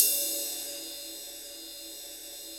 Index of /90_sSampleCDs/Roland L-CDX-01/CYM_Rides 1/CYM_Ride Modules
CYM 18  RD2.wav